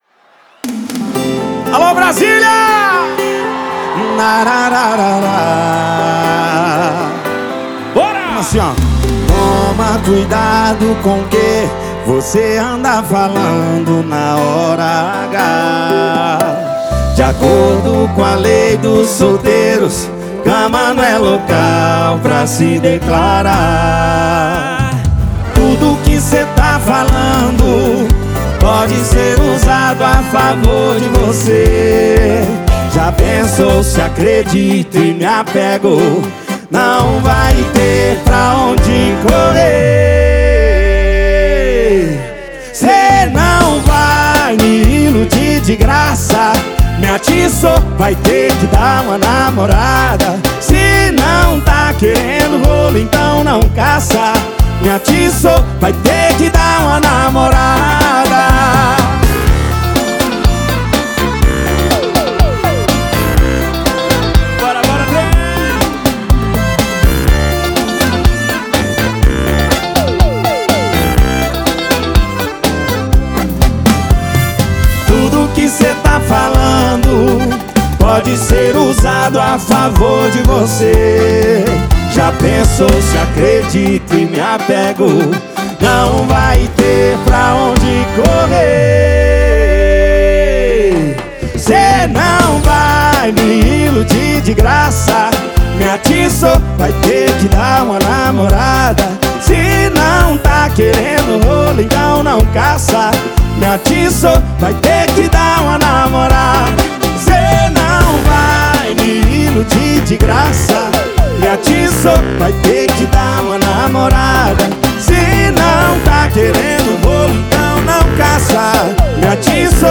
Eletronica
Funk
PANCADÃO
SERTANEJO